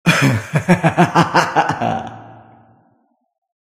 Laugh.ogg